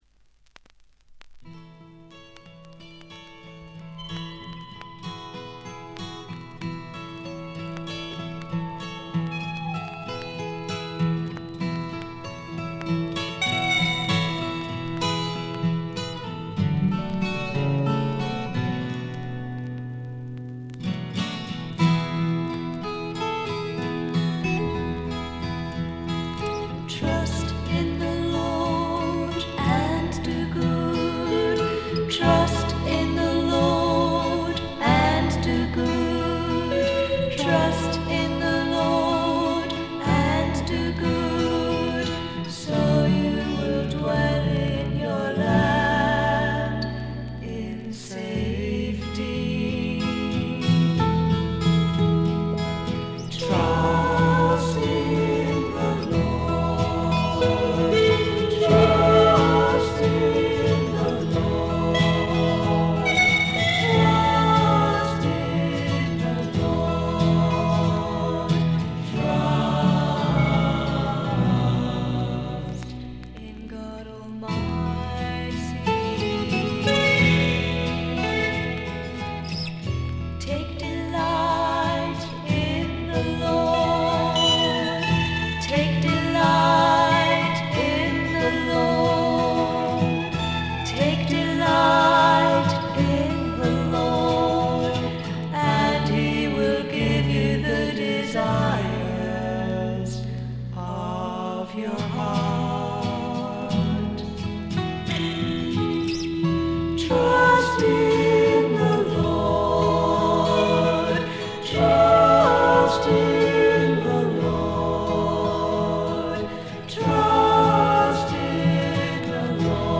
バックグラウンドノイズ、チリプチ多め大きめ。
妖精フィメール入り英国ミスティック・フォーク、ドリーミー・フォークの傑作です。
霧深い深山幽谷から静かに流れてくるような神秘的な歌の数々。
それにしても録音の悪さが幸いしてるのか（？）、この神秘感は半端ないです。
試聴曲は現品からの取り込み音源です。